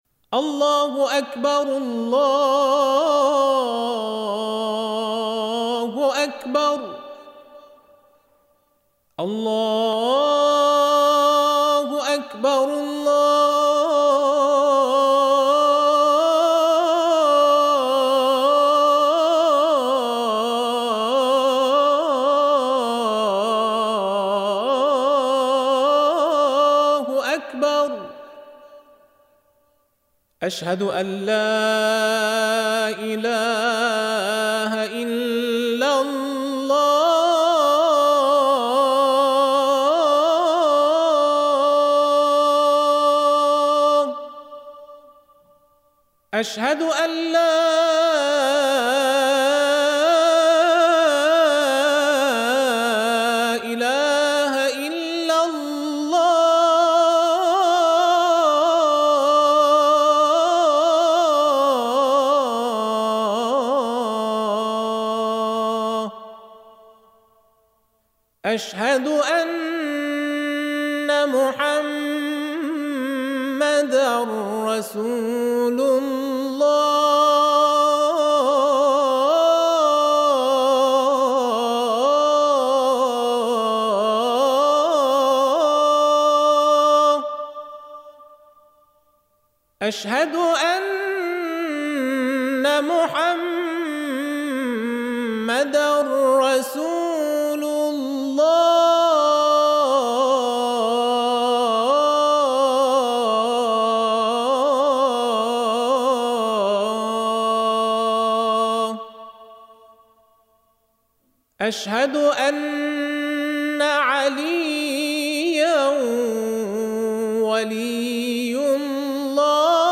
فایل صوتی اذان